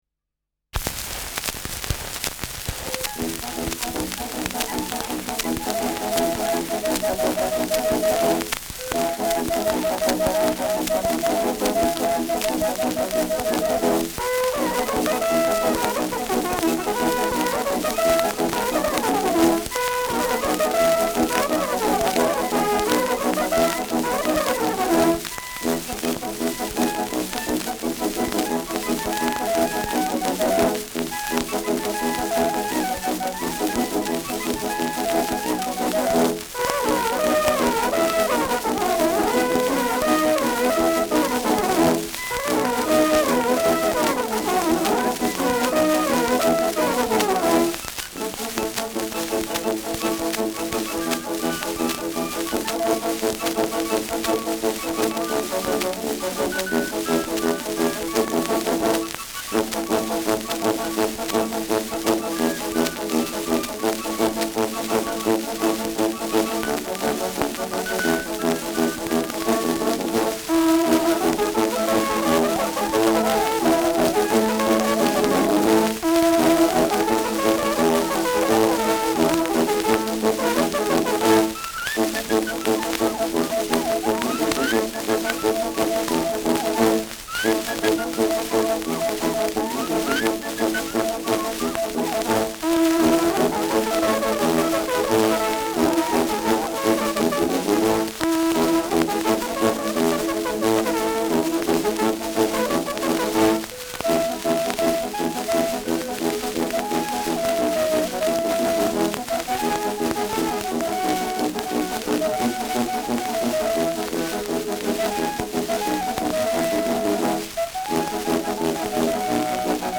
Schellackplatte
präsentes Rauschen : Knacken